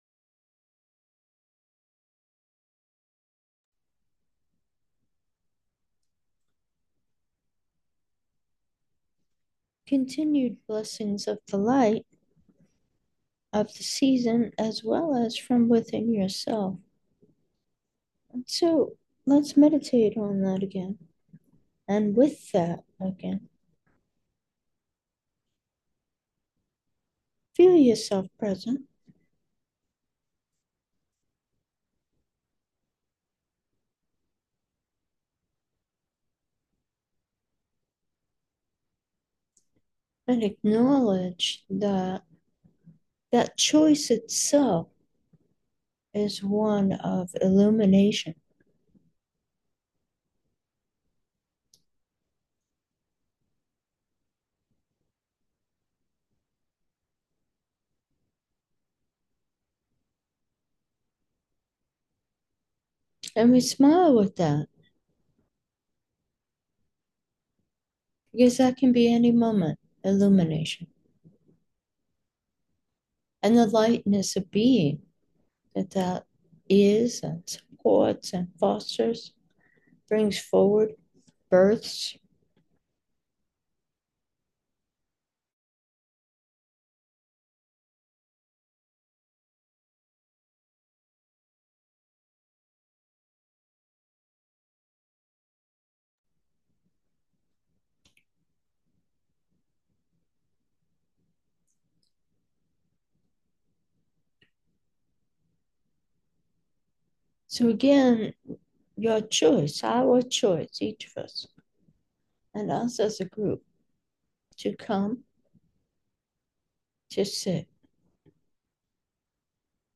Meditation: may the love in my heart …